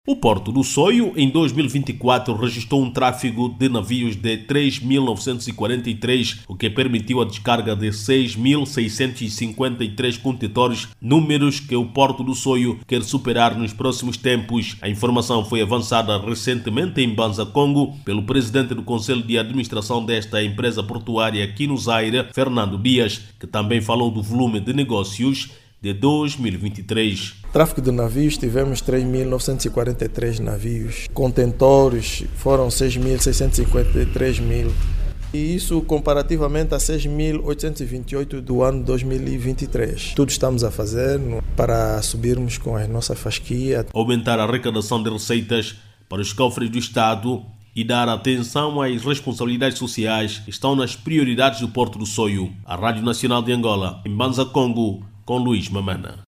O Jornalista